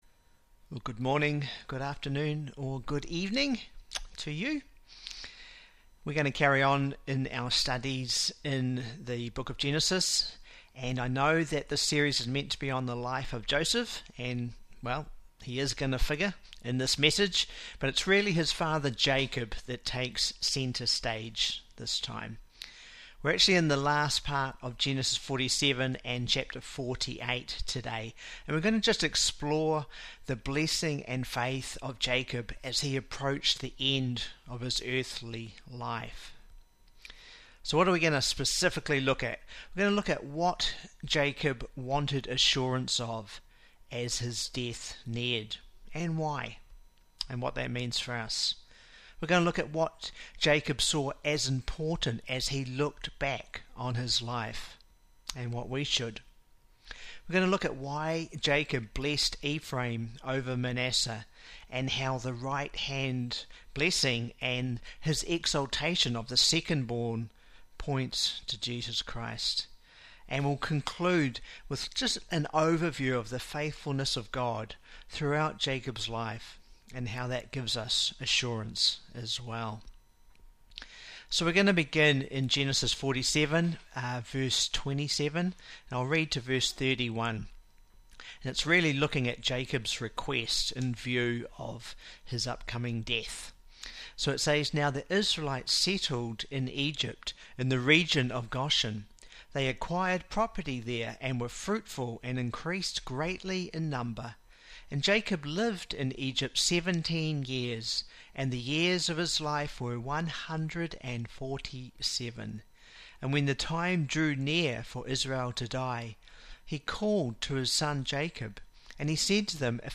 Genesis 48 Lesson: Jacob's faith facing the end (audio)